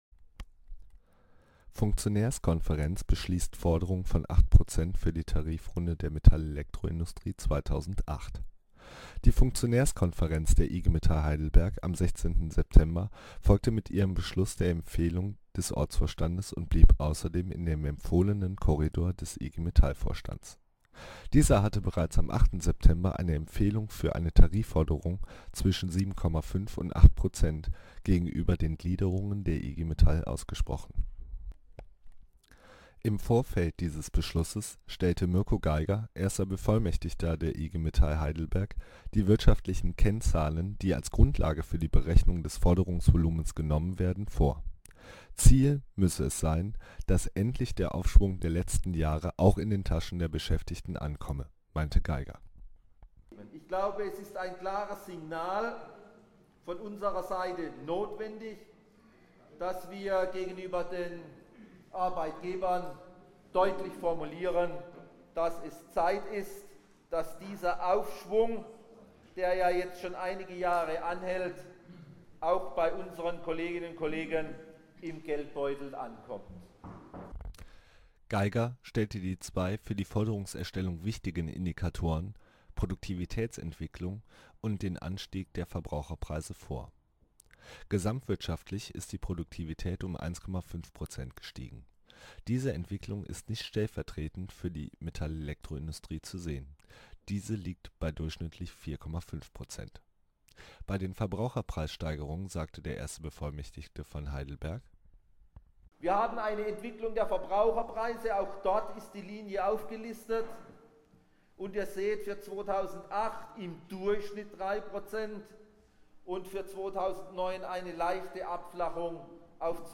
Bericht über den Forderungsbeschluss